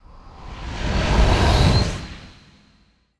dark_wind_growls_03.wav